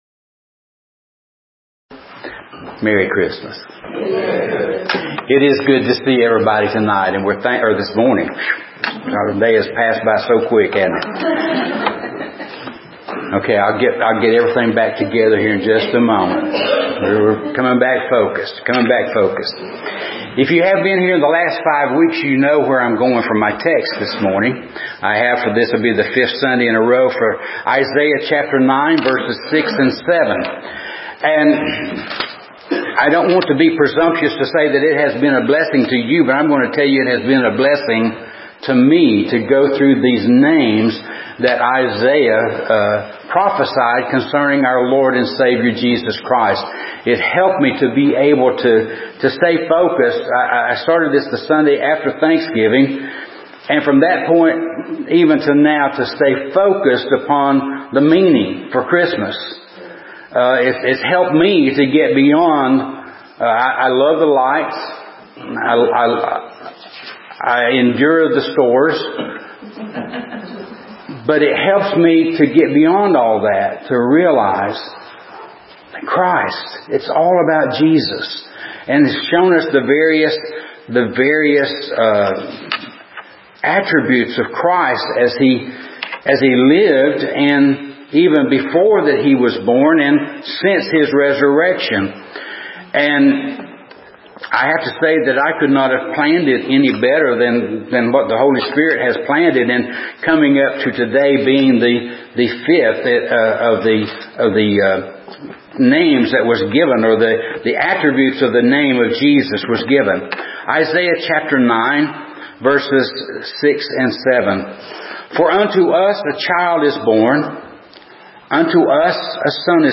The Prince Of Peace Apr 6 In: Sermon by Speaker